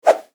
Download Swoosh sound effect for free.
Swoosh